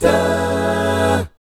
1-F#MI7 AA-R.wav